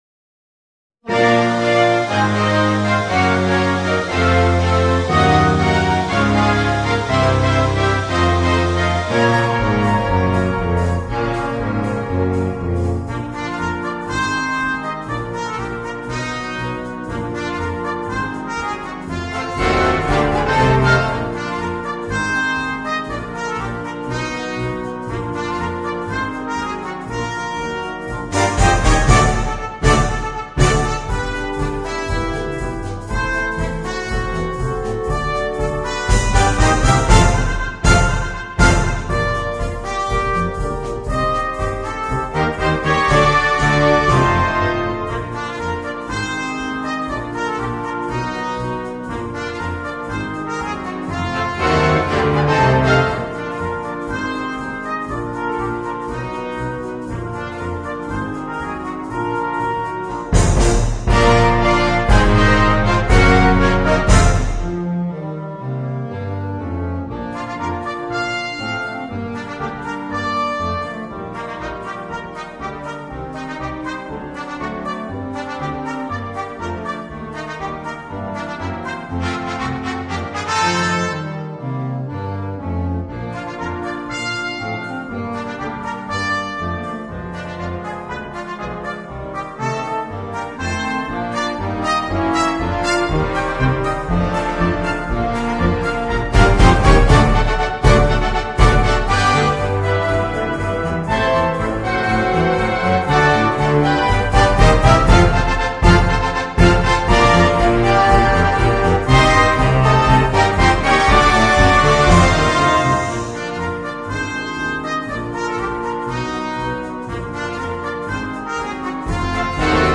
Per tromba e banda